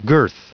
Prononciation du mot girth en anglais (fichier audio)